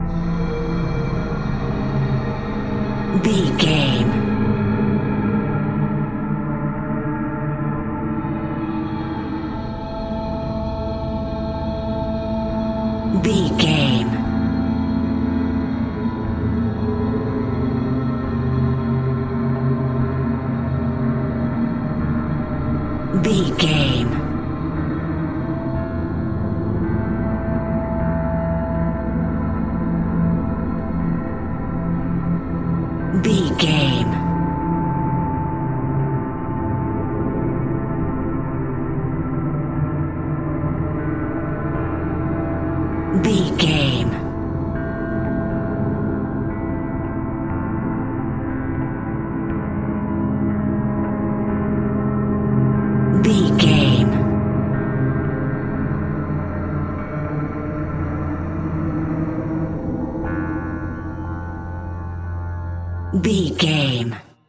Ionian/Major
A♭
industrial
dark ambient
EBM
drone
experimental
synths